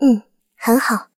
追猎者获得资源语音.OGG